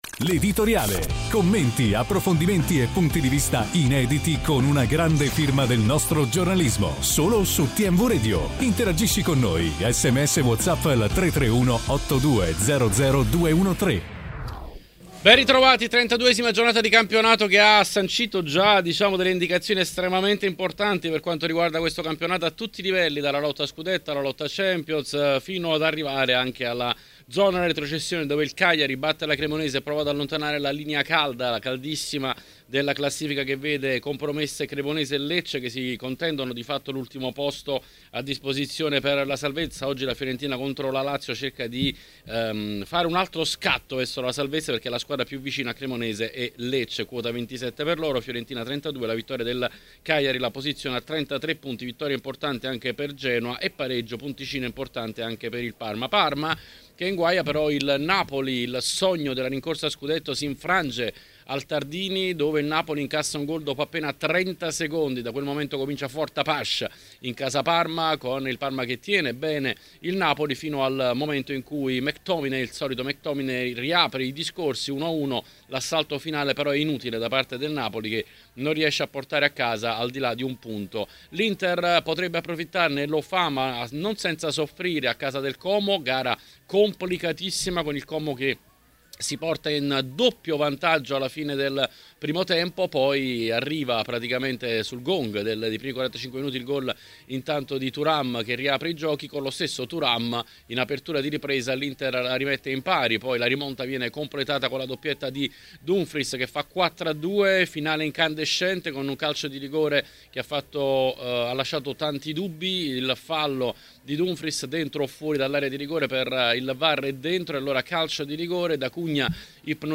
intervenuto ai microfoni di TMW Radio